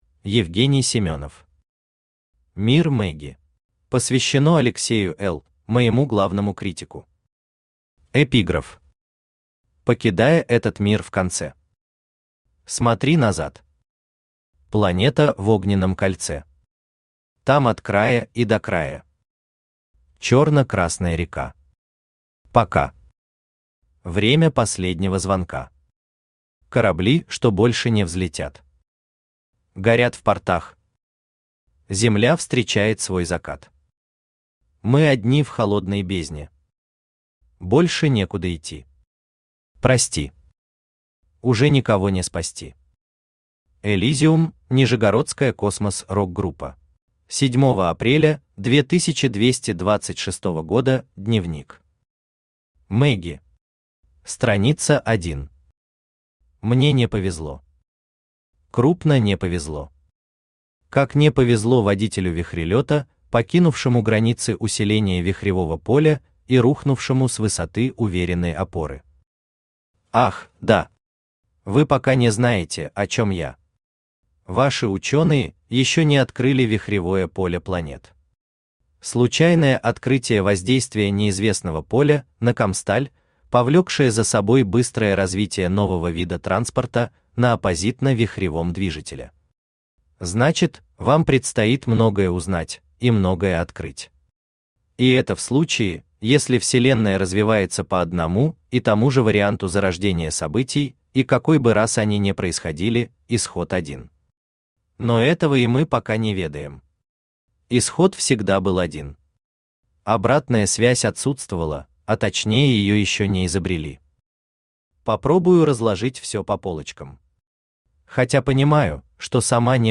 Аудиокнига Мир Мэгги | Библиотека аудиокниг
Aудиокнига Мир Мэгги Автор Евгений Семенов Читает аудиокнигу Авточтец ЛитРес.